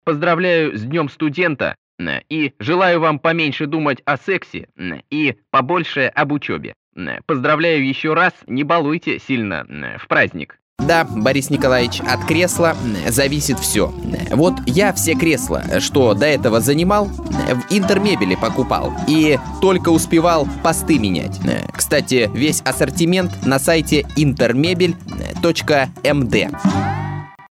Озвучка голосом Владимира Путина. Запись профессионального пародиста
Категория: пародии
Характеристика: Пародист